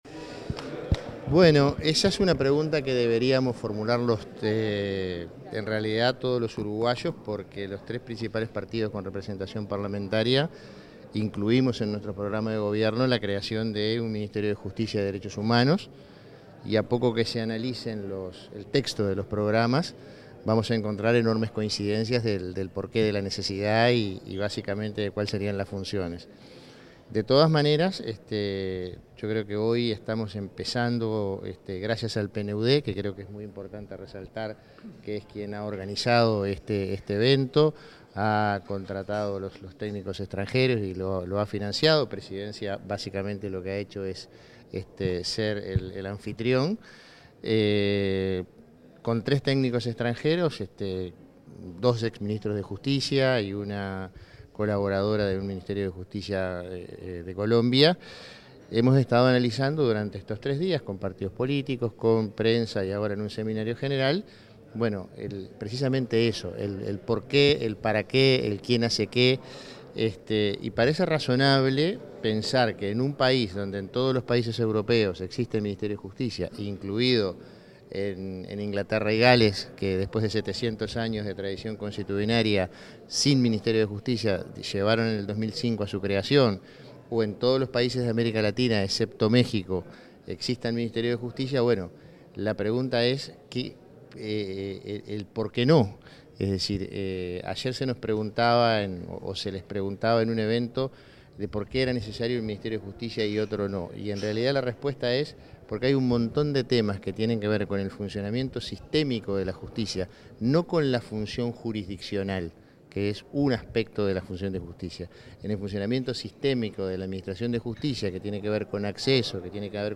Declaraciones del prosecretario de la Presidencia, Jorge Díaz
Declaraciones del prosecretario de la Presidencia, Jorge Díaz 25/06/2025 Compartir Facebook X Copiar enlace WhatsApp LinkedIn El prosecretario de Presidencia de la República, Jorge Díaz, realizó declaraciones a la prensa tras el seminario internacional Hacia un Ministerio de Justicia y Derechos Humanos en Uruguay, que se desarrolló en la Torre Ejecutiva.